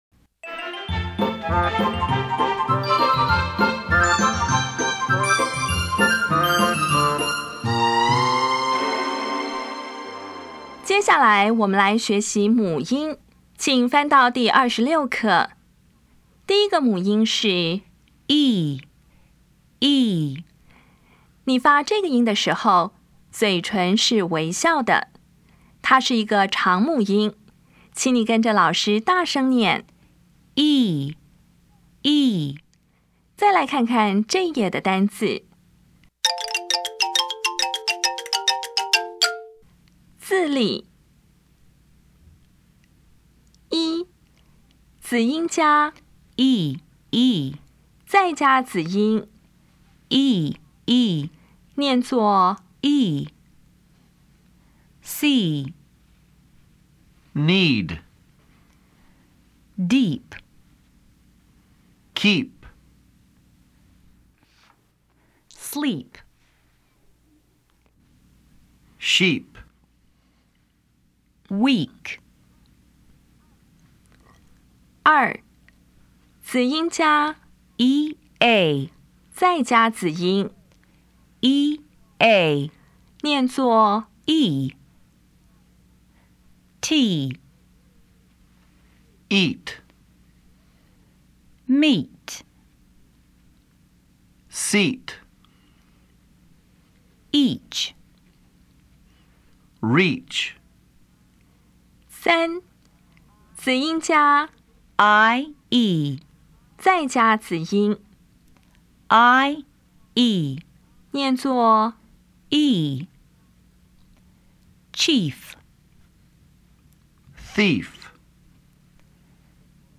当前位置：Home 英语教材 KK 音标发音 母音部分-2: 长母音 [i]
音标讲解第二十六课
1, 子音ee子音
2, 子音ea子音